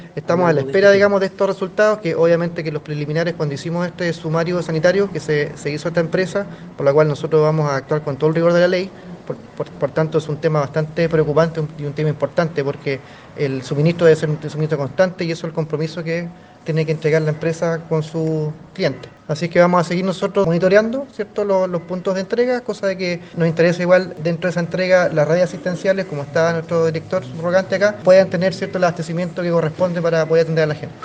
Por su parte, el jefe de la Autoridad Sanitaria de Osorno, Felipe Vergara, aseguró que se están realizando los análisis respectivos a las aguas contaminadas, lo que conllevará sumarios sanitarios hacia la empresa Essal.